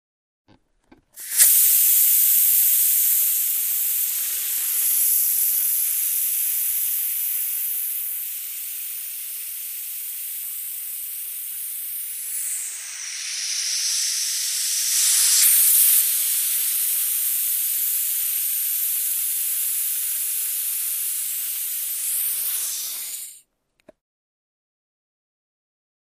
Ball: Air Release; Long Hiss Of Air Being Released From Ball, Close Perspective.